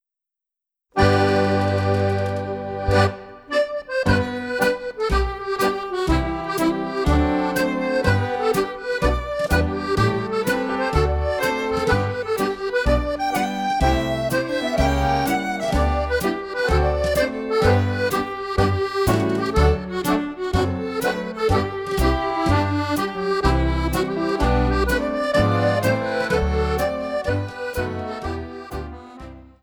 Strathspey